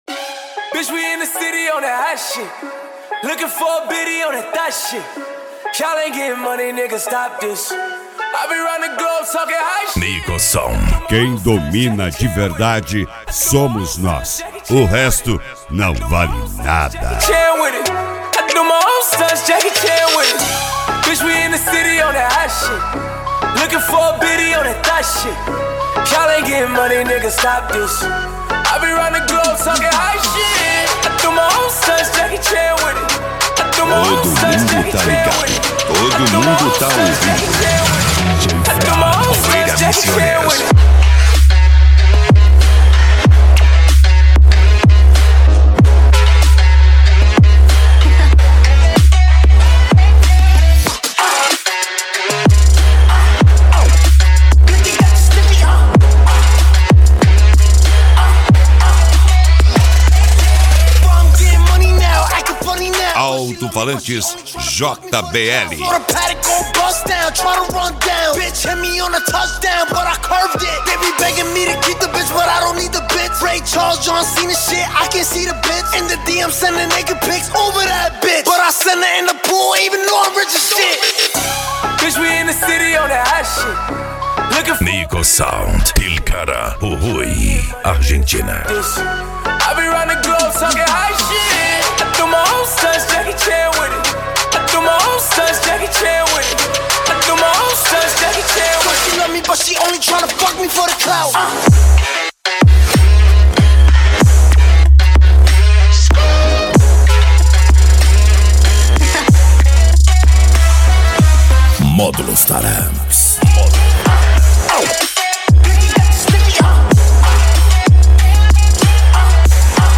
Bass
Electro House
Eletronica
Psy Trance
Remix